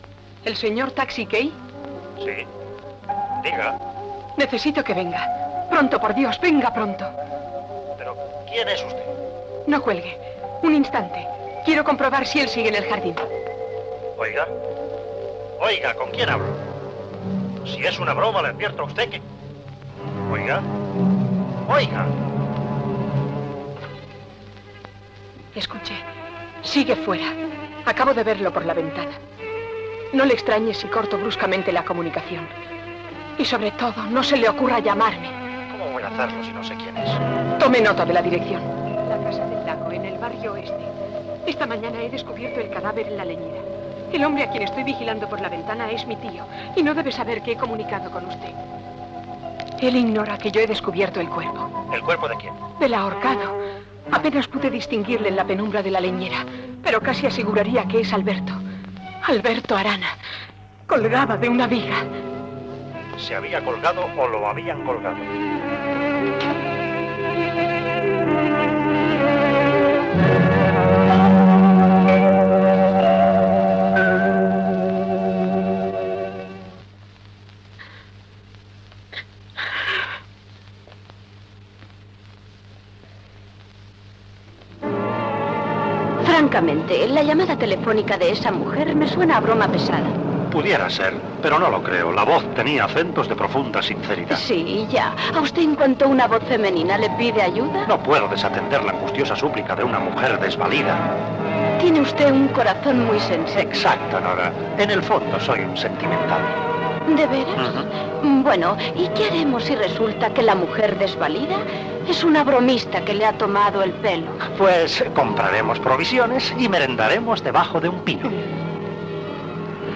Ficció